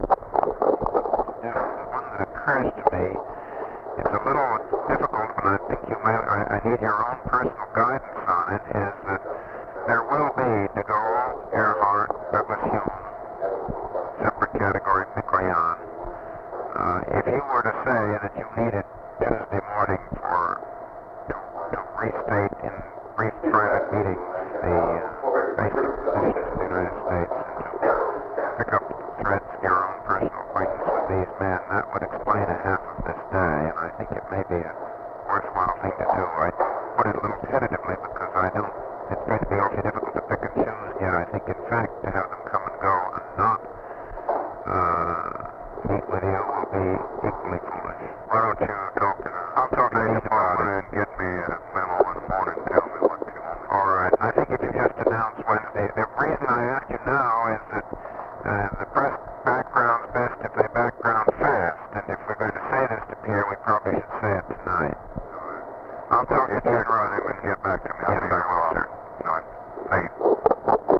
Conversation with DAVID DUBINSKY, November 24, 1963
Secret White House Tapes